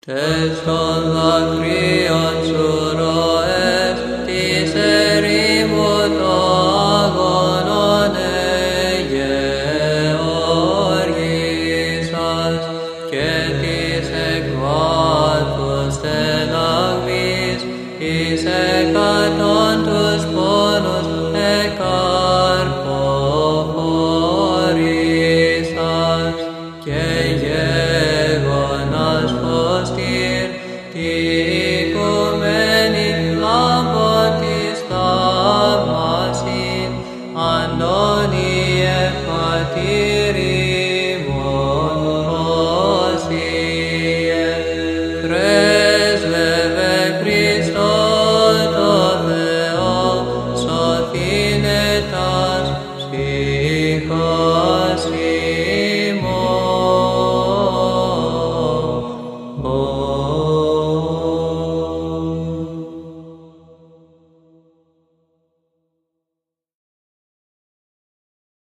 Index of: /public/Βυζαντινή/Ι.Μ. Αγίου Αντωνίου (Αμερική Αριζόνα)/Αγρυπνία/
14-ΑΠΟΛΥΤΙΚΙΟΝ ΑΓΙΟΥ ΑΝΤΩΝΙΟΥ(ΗΧΟΣ ΠΛ Δ').mp3